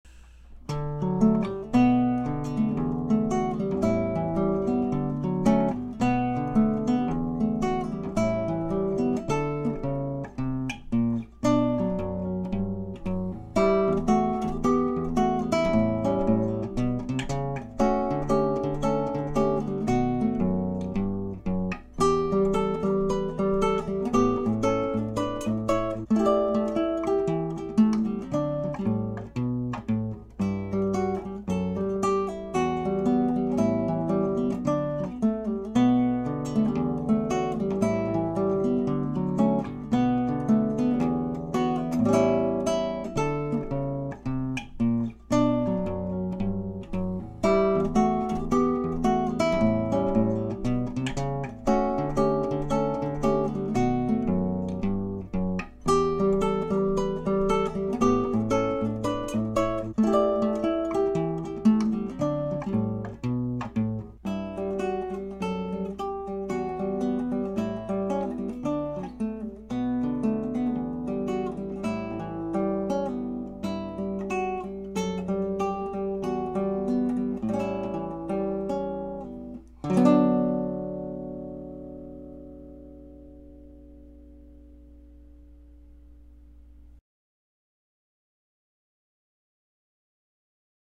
acoustic and electric guitar